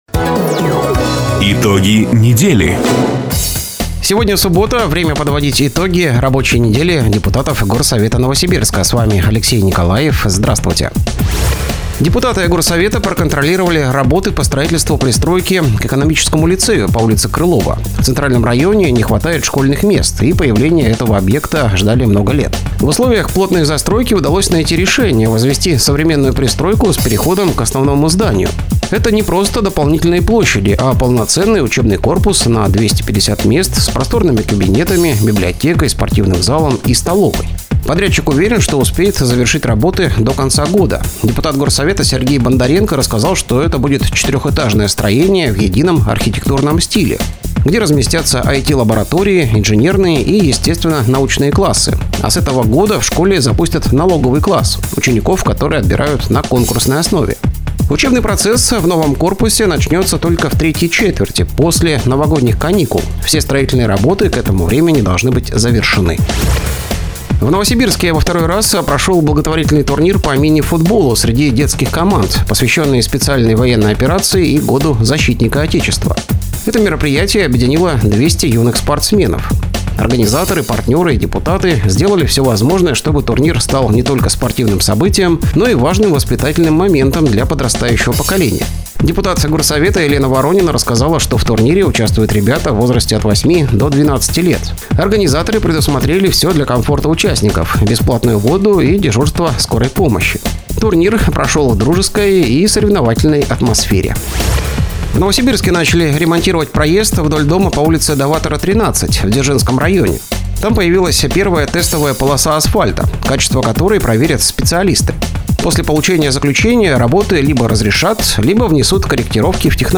Запись программы "Итоги недели", транслированной радио "Дача" 28 июня 2025 года